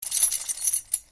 It's hard to see anything, but you hear a jingling sound.
keys.mp3